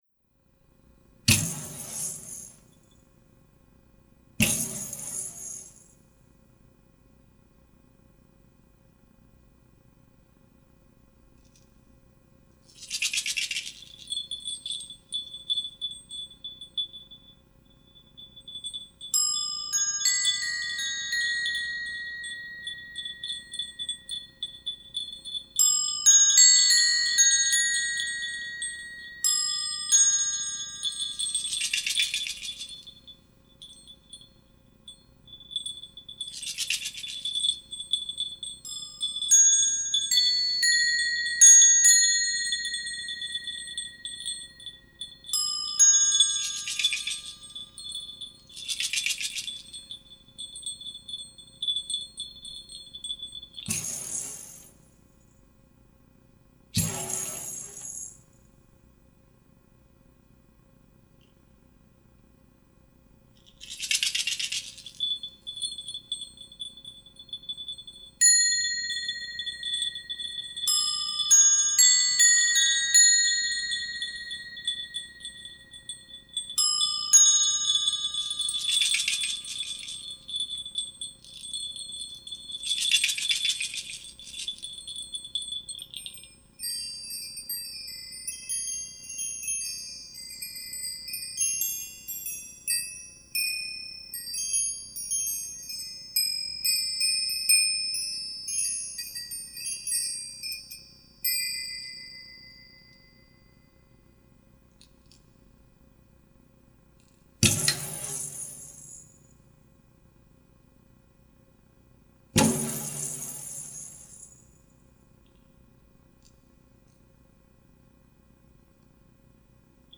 me and some other toys